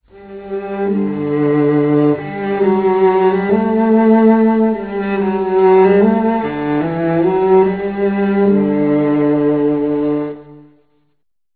Play Sound Play Sound Play Sound The Cello is a bowed string instrument of the violin family, pitched lower than the viola but higher than the double bass. It consists of four strings suspended over a long fingerboard and large resonating body of wood.
cello.ram